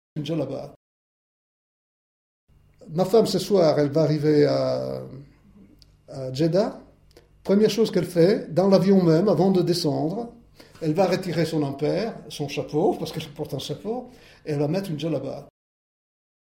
uitspraak voorbeeld Ma femme ce soir elle va arriver à Djeddah.